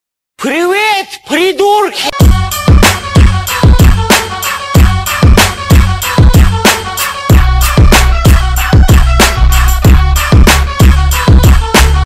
Музыкальное приветствие привет, придурк
• Категория: Привет(приветствие)